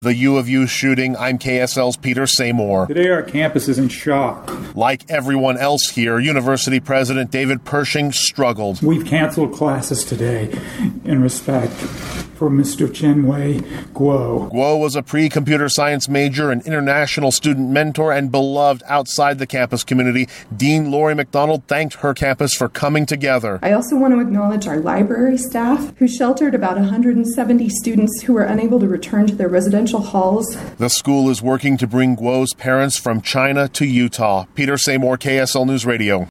U. administrators tear up recalling murdered student